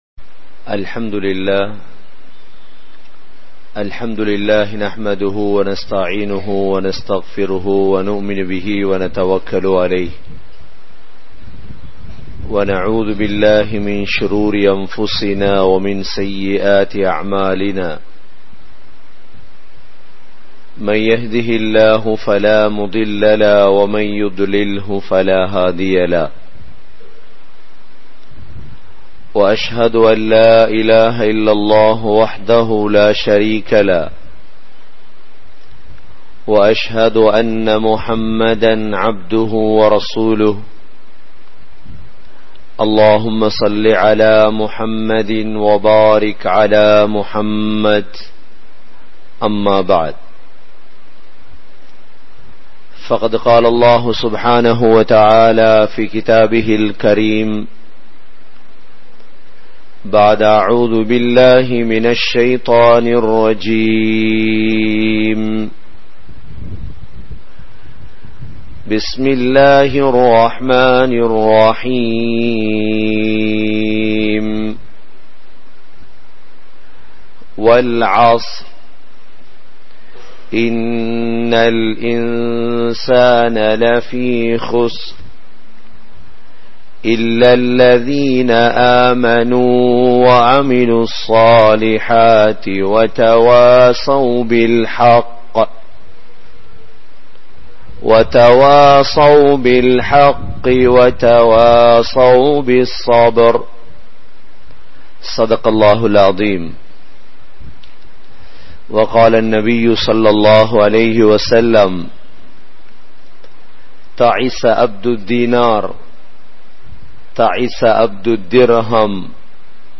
Matara, Muhiyadeen Jumua Masjith